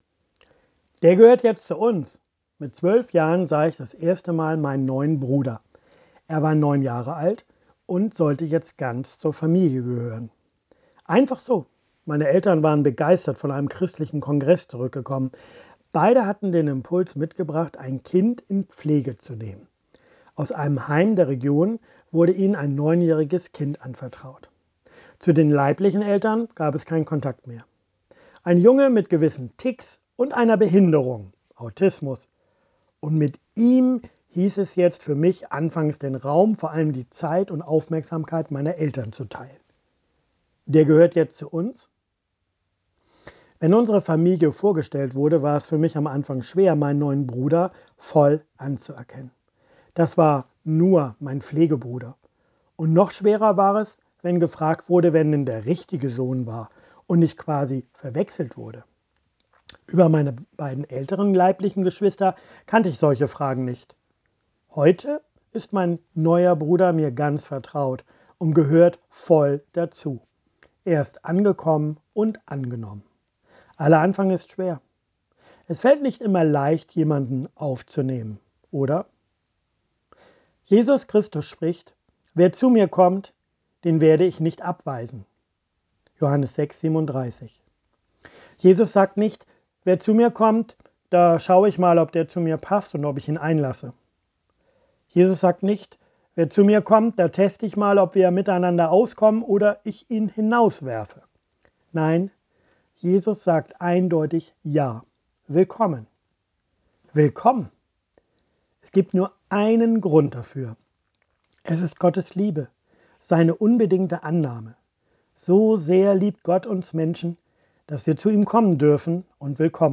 Der gehört zu uns. Kurzandacht zur Jahreslosung 2022